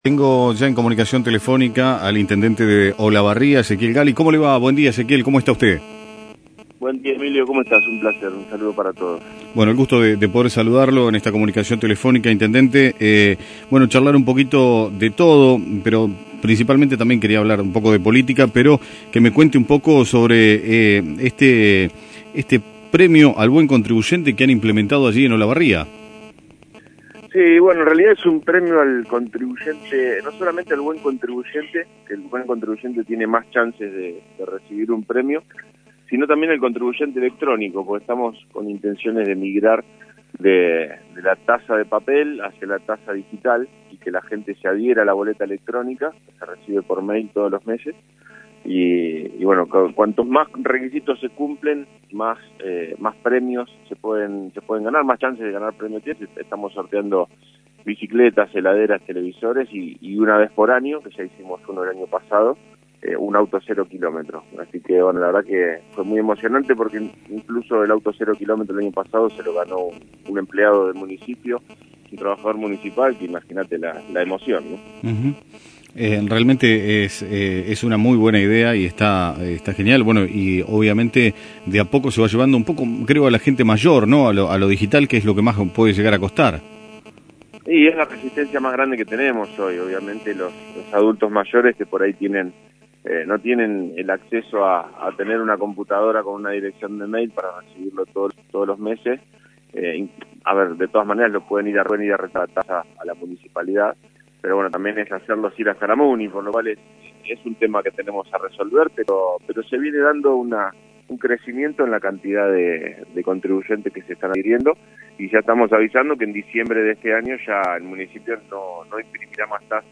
El intendente de Olavarria Ezequiel Galli, en una entrevista realizada en AM 1210 por La Gran Mañana, habló sobre la realización del primer sorteo 2023: Premio al Buen Contribuyente Electrónico, su posible reelección y la visita del diputado nacional Diego Santilli en el día de hoy.